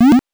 8 bits Elements
powerup_33.wav